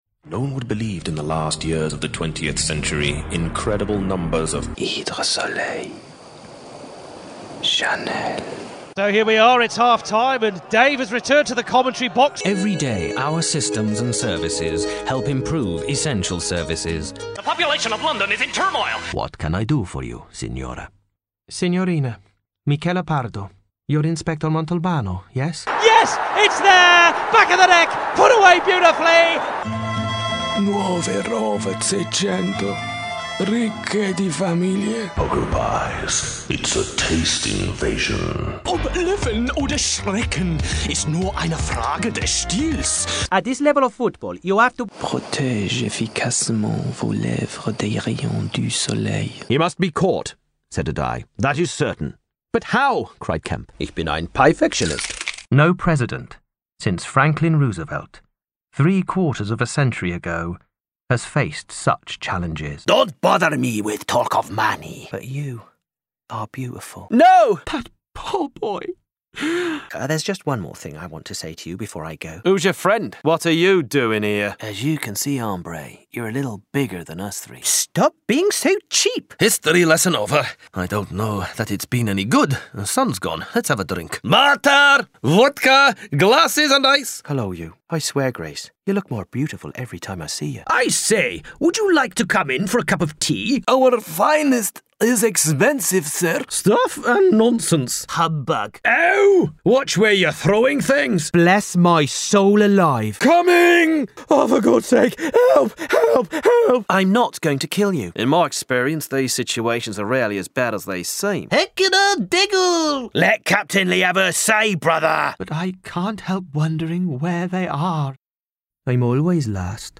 click here to a play a 2 minute voice demo clip (mp3)) including: his own publications of A Christmas Carol and Gulliver's Travels - Part One A Voyage to Lilliput under the furicano label and has taken a wide variety of roles in BBC and Independent Radio Dramas, including; Captain Corelli in Captain Corelli's Mandolin and El Vici in An Angel at My Table both for BBC Radio Drama, The Chronicles of Narnia, and the Award-winning Cost of Freedom for Focus on the Family.